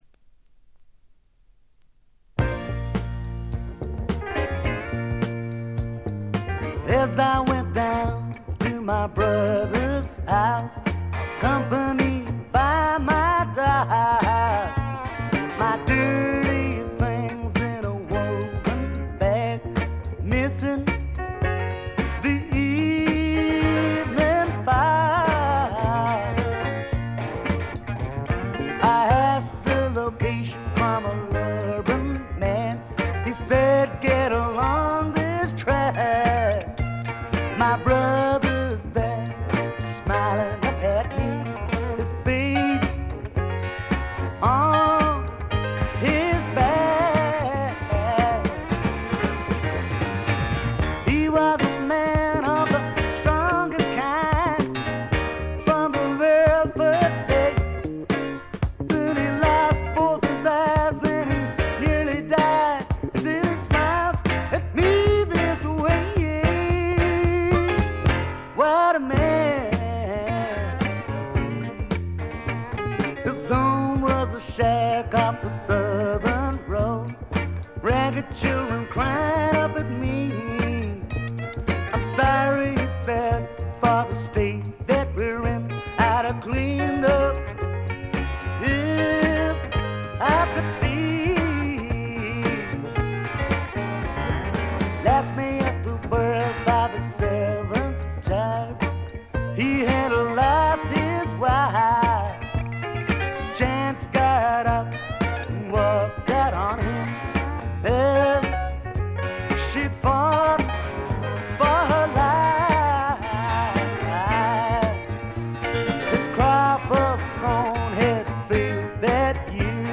ごくわずかなノイズ感のみ。
試聴曲は現品からの取り込み音源です。
Recorded at:Nova Sounds and Island Studios
vocal and guitar